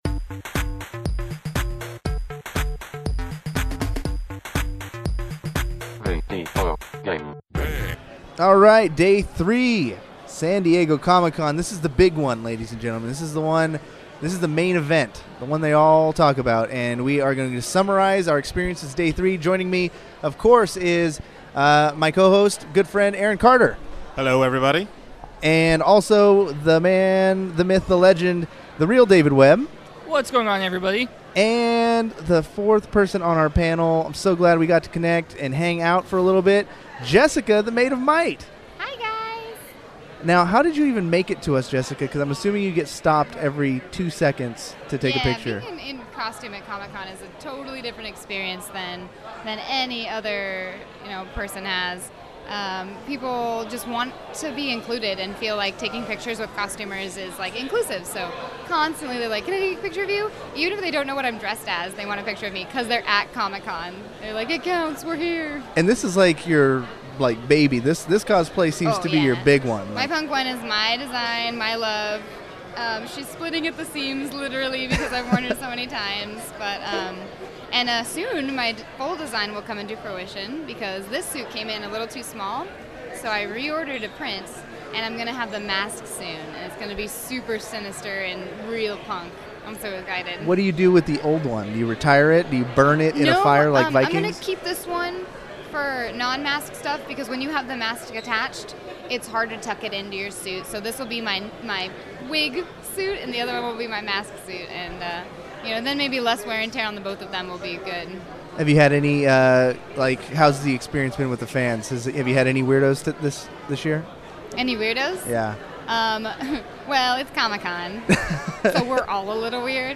VGB once again turns the Marriott Marquis in San Diego into their on location studio and this time we have the full crew!